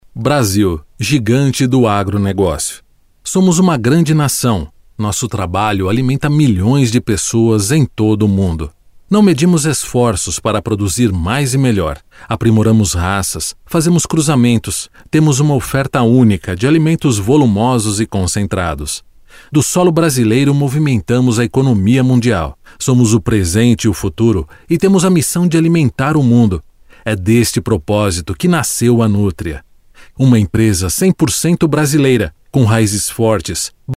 男葡04 葡萄牙语男声 巴西葡萄牙 广告宣传片人物角色老人【汇总】 低沉|激情激昂|大气浑厚磁性|沉稳|娓娓道来|科技感|积极向上|时尚活力|神秘性感|调性走心|感人煽情|素人